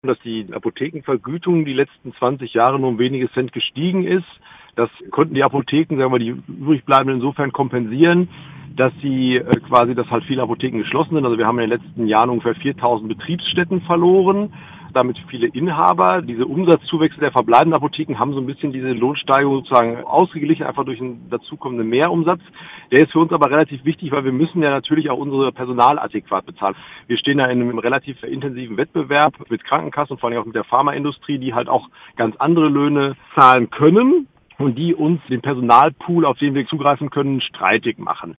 Radio Ennepe Ruhr Interview